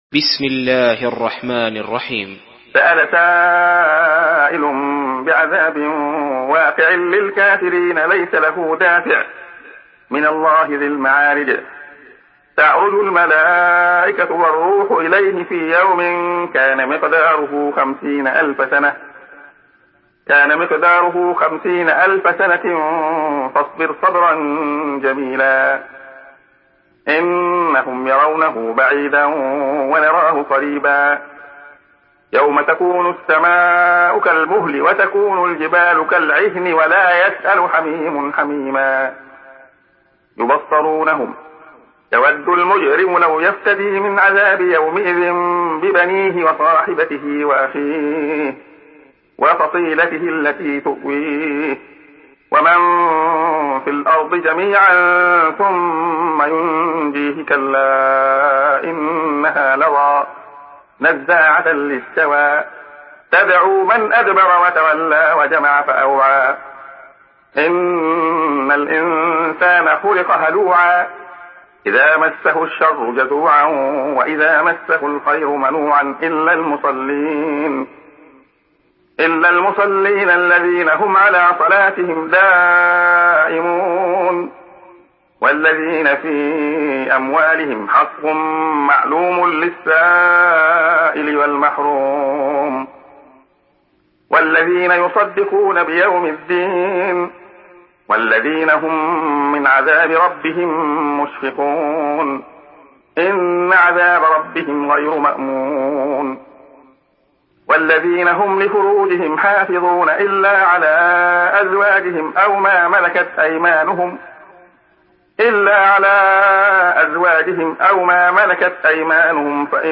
Surah Mearic MP3 by Abdullah Khayyat in Hafs An Asim narration.
Murattal Hafs An Asim